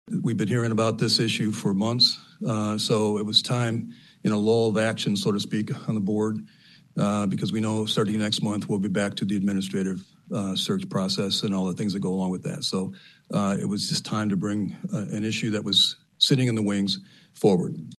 During Tuesday night’s monthly Board of Commissioners business meeting at the Fillmore Street complex in West Olive, a proposal to, “approve the elimination of the Ottawa County motto of ‘Where Freedom Rings'” passed by a 7-4 margin.
Board chairman John Teeples of Jenison explained what led to placing this matter for consideration at this time.